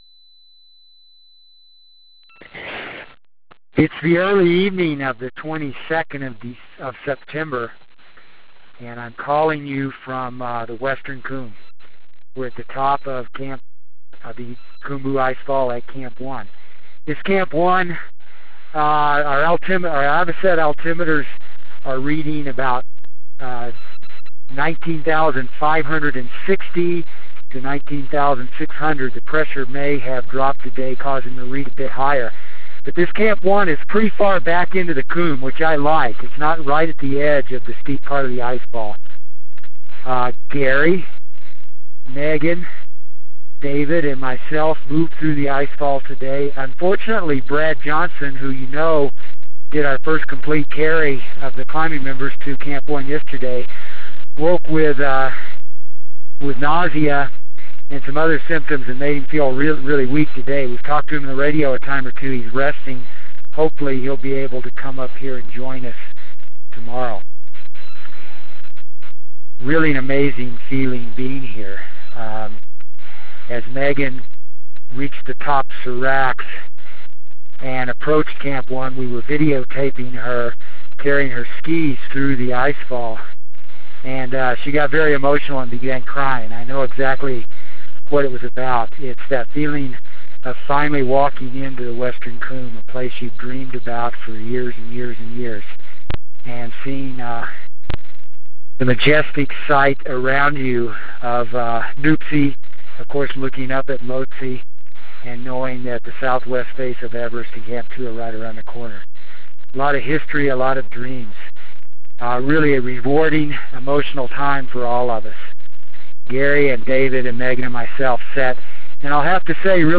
September 22 - From the Western Cwm